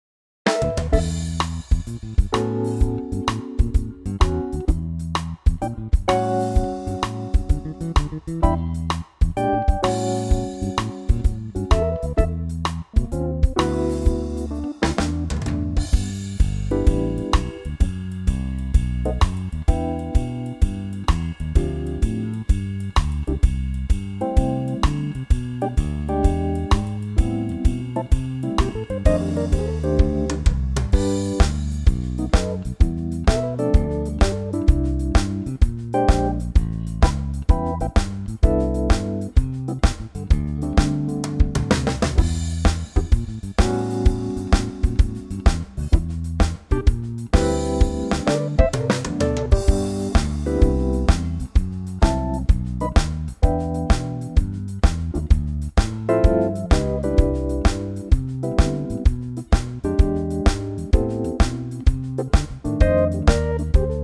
Unique Backing Tracks
key - G - vocal range - D to E
A super cool swinging arrangement